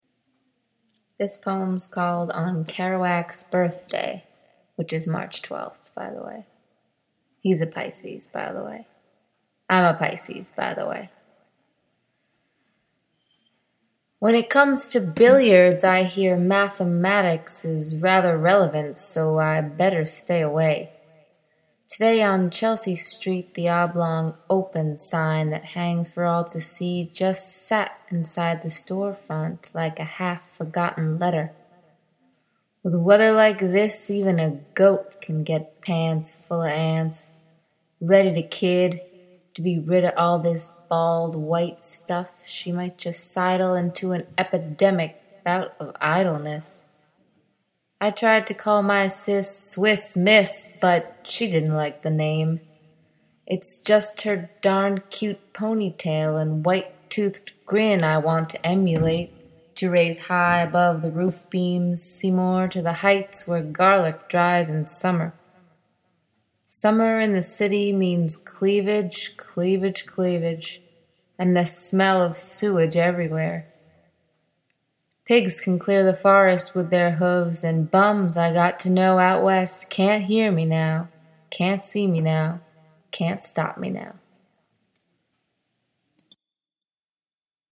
Poem for Kerouac; poem out loud (mine) (you can listen to it).